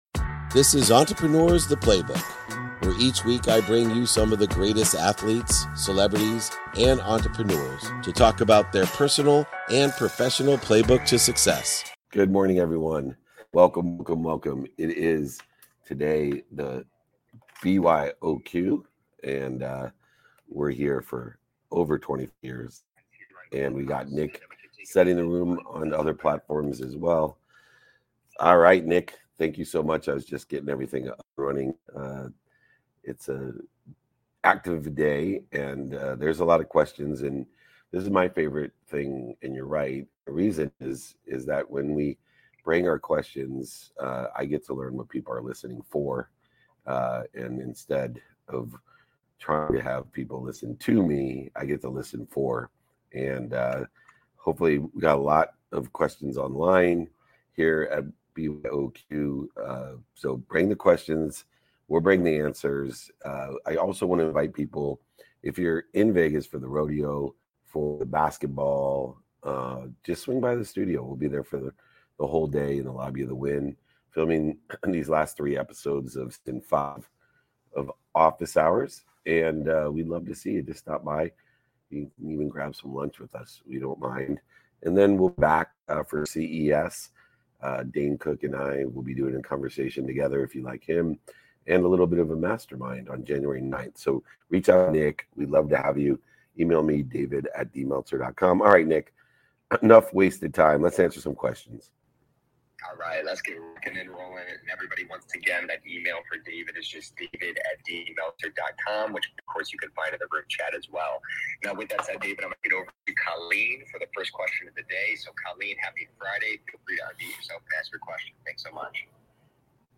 In this episode, I'm taking questions from around the room, engaging in vibrant discussions on intuition, awareness, education, and the nuanced role of ego.